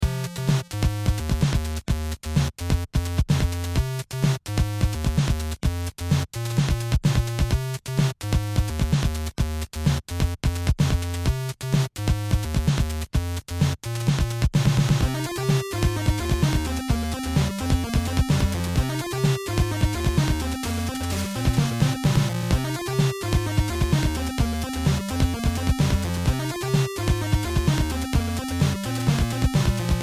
programmatically generated 8-bit musical loops